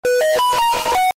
Samsung Notification (bass Boosted)